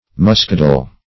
Muskadel \Mus"ka*del`\ (m[u^]s"k[.a]*d[e^]l`)